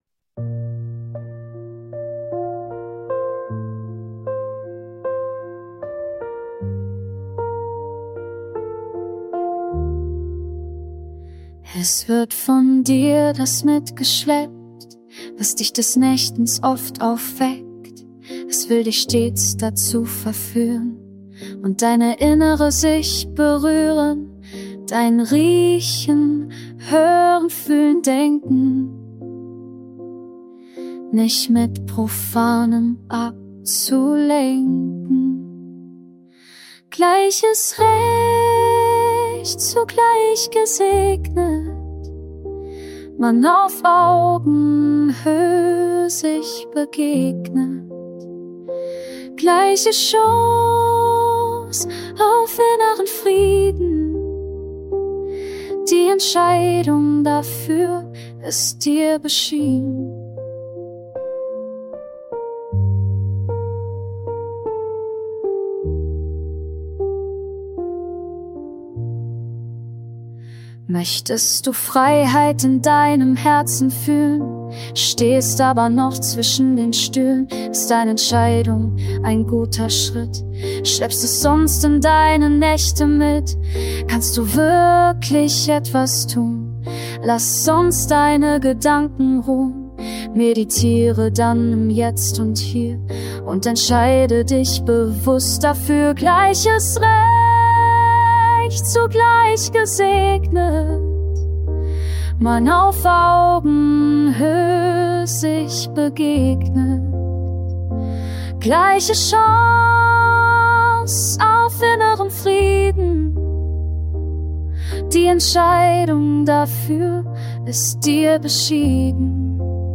Reggae
Ballade